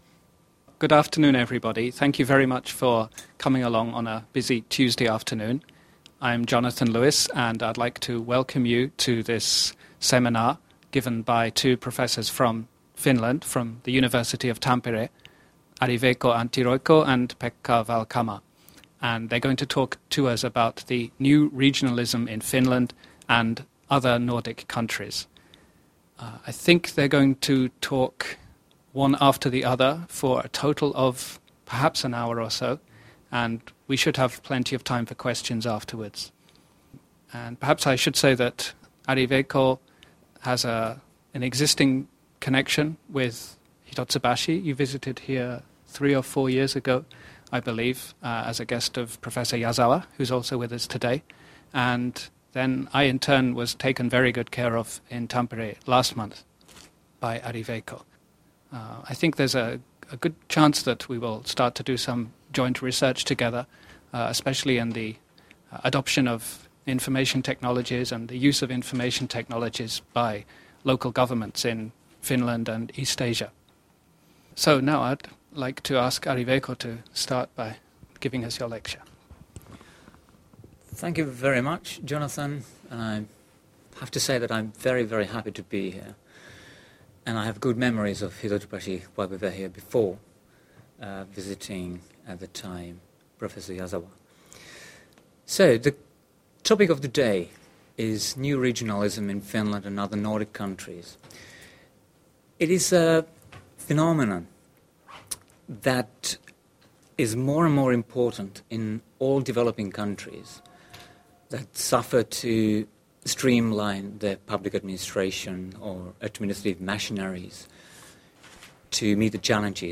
New Regionalism in Finland and other Nordic Countries. Lecture presented in the University of Tampere, Finland.